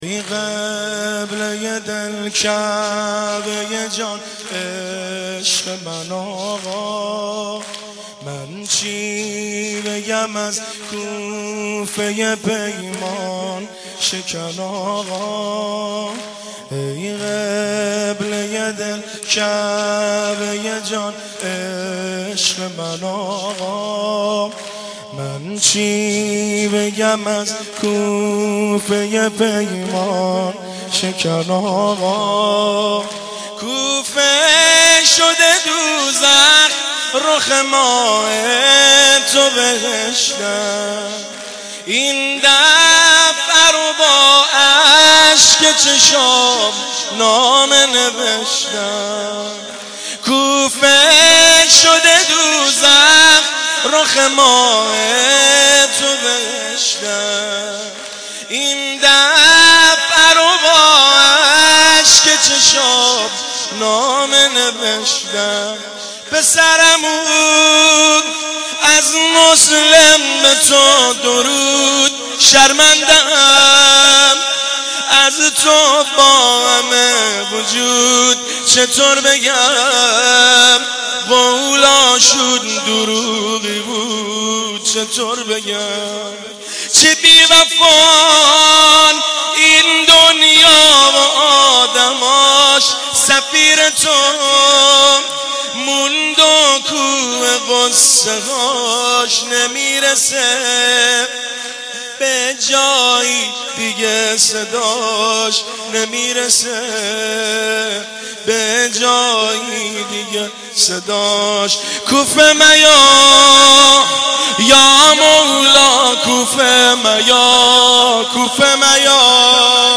خیمه گاه - هیئت بین الحرمین طهران - زمینه - این قبله دل ، کعبه جان ، عشق من آقا
هیئت بین الحرمین طهران